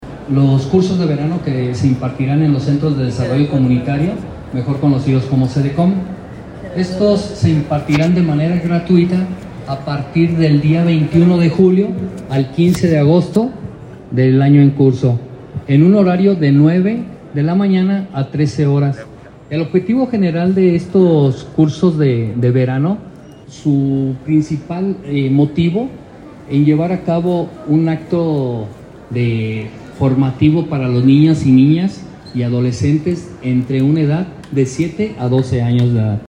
AudioBoletines